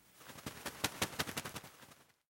Звуки мотылька
На этой странице собраны уникальные звуки мотыльков: от легкого шелеста крыльев до едва уловимого трепета в ночи.
Звук мотылька бьющегося об лампу